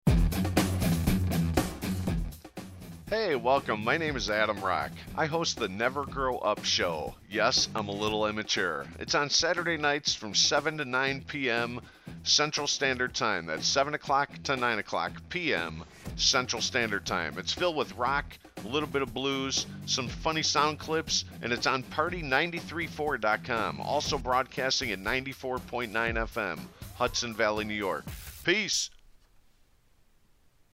Straight Focus Design near Chicago offers voice over work and a voice artist
Radio Show Promo - > :30 seconds